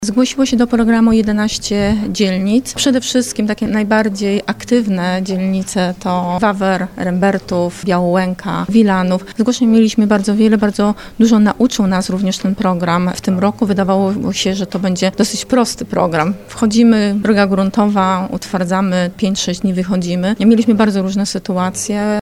Na konferencji prasowej przedstawiciele ZDM i miasta podsumowali ostatnie dwanaście miesięcy prac remontowych na terenie całej Warszawy.
Wiceprezydent Warszawy Renata Kaznowska przekonuje, że program utwardzania będzie prowadzony dalej, a miasto nauczone doświadczeniem przygotuje się lepiej na te inwestycje.